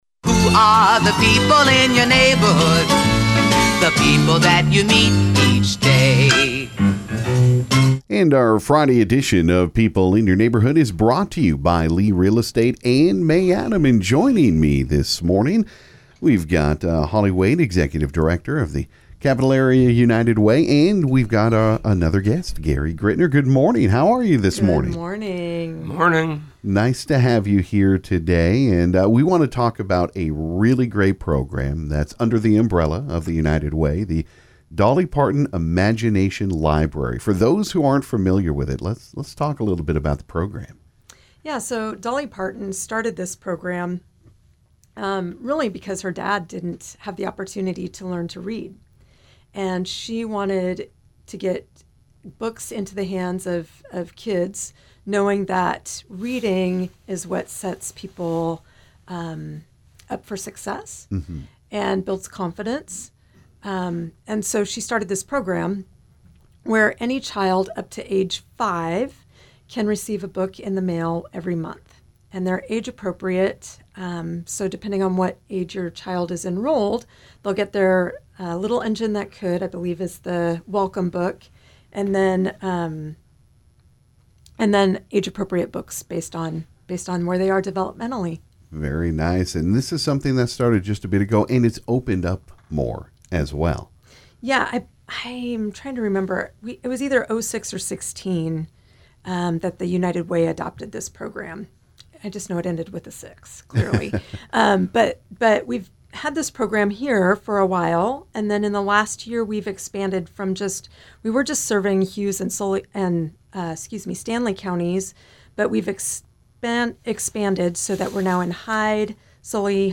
This morning on KGFX reading was the topic on People In Your Neighborhood